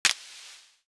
Sizzle Click 6.wav